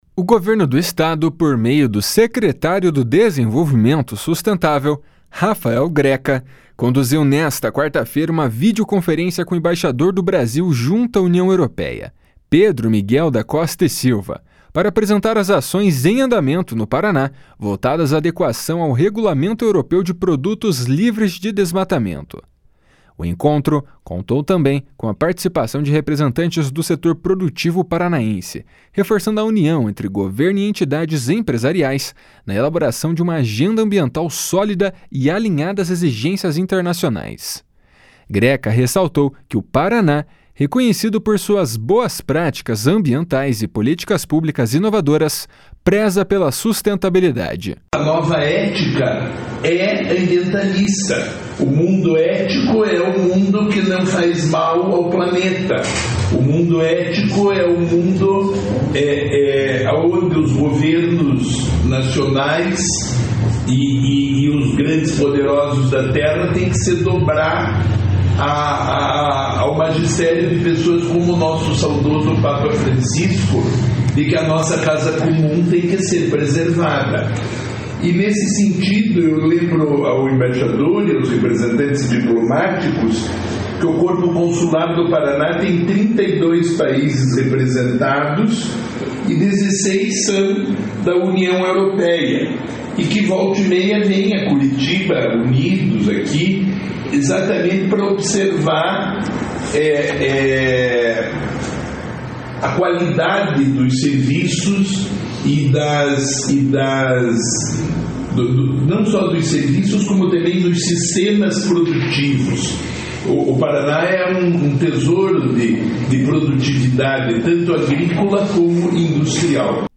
// SONORA RAFAEL GRECA //